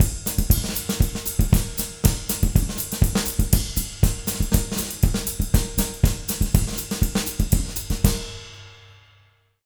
240SAMBA03-L.wav